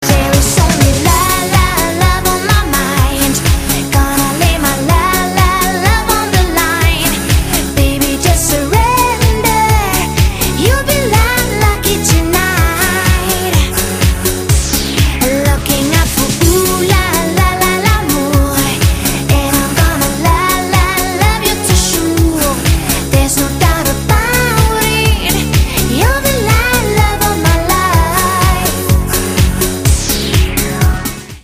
分类: DJ铃声
高潮段 DJ舞曲